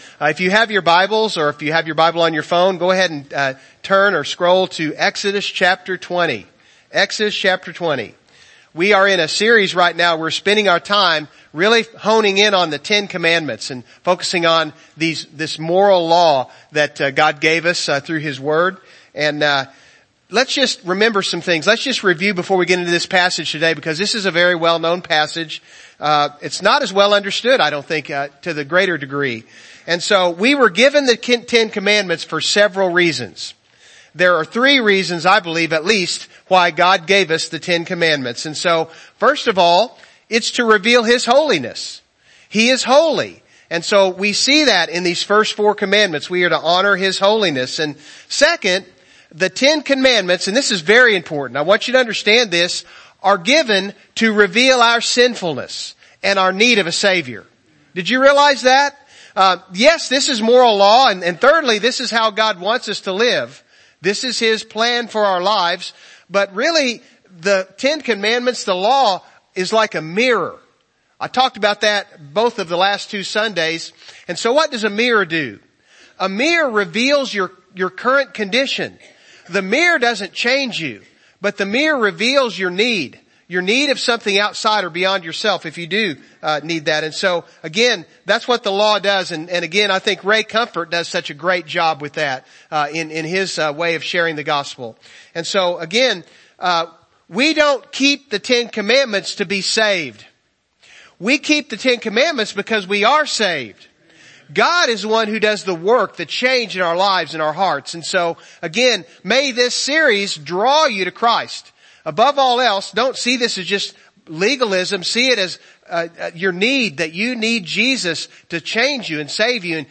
God's Top Ten Service Type: Morning Service « Acts